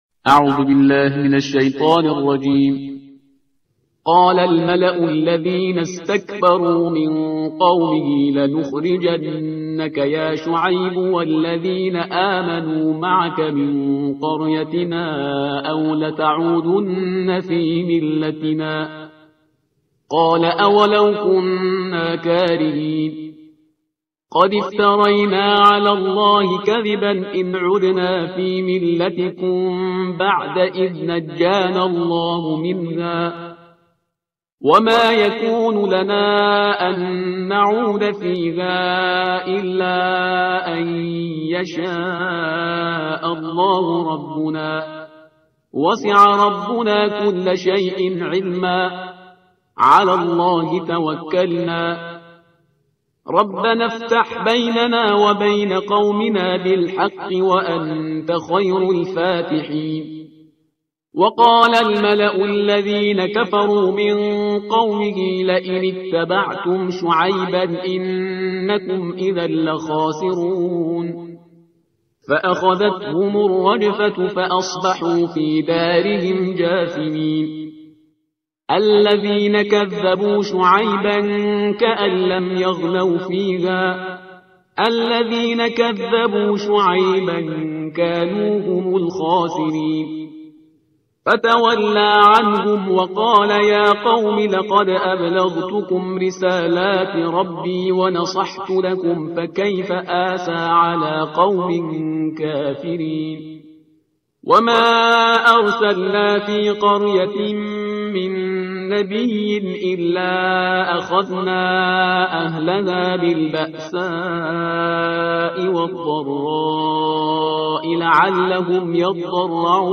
ترتیل صفحه 162 قرآن